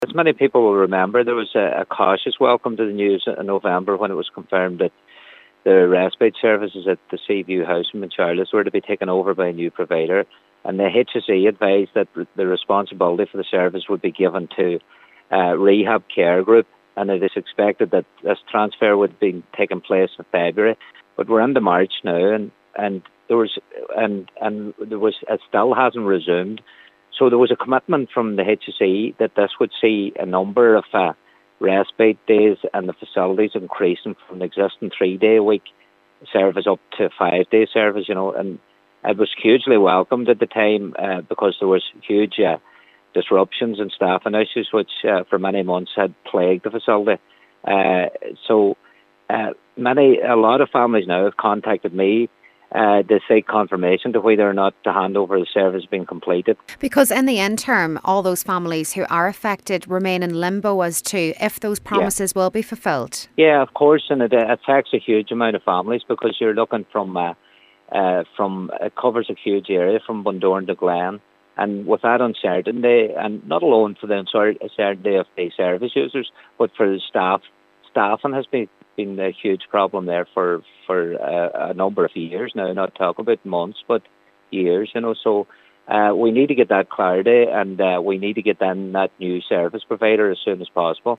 Local Cllr Noel Jordon says promises were made as part of the handover and families are concerned to if and when they will be fulfilled.